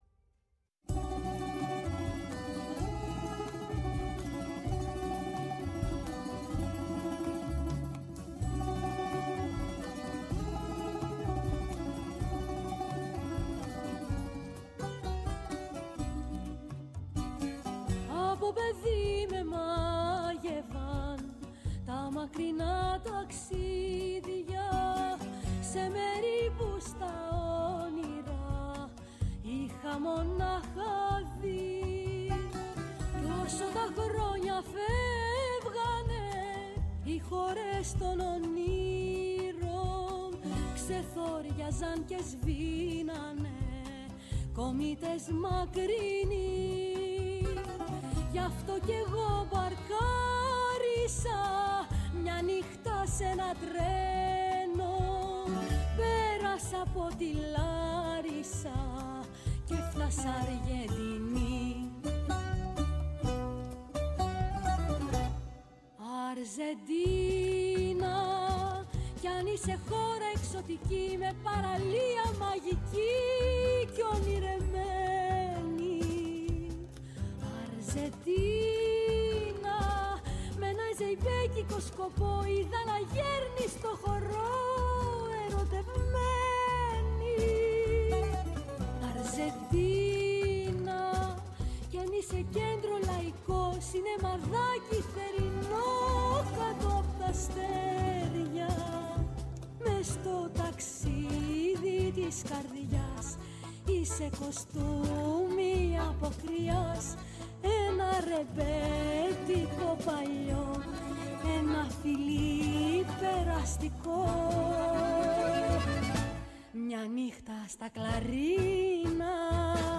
Στο στούντιο της “Φωνής της Ελλάδας”